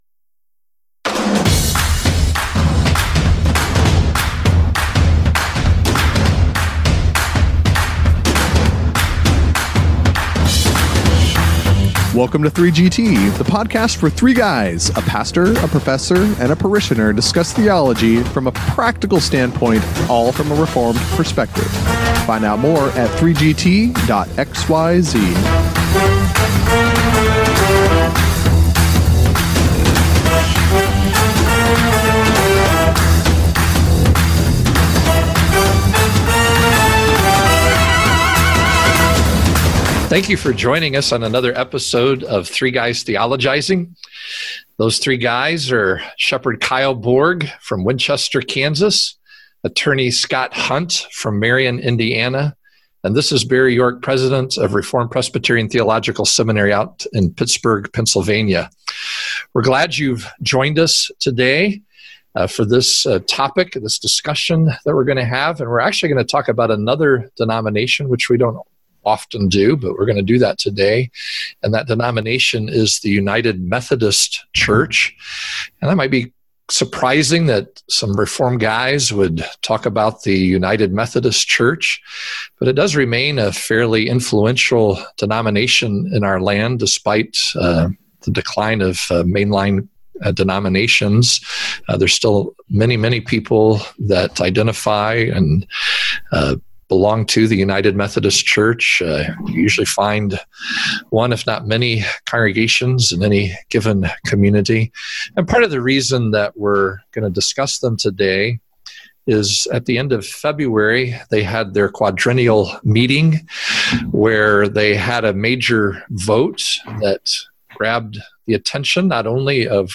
A few weeks ago the United Methodist Church in their quadrennial meeting voted to uphold traditional marriage and non-LGBTQ ordination. So the 3GTers discuss the significance of this outcome.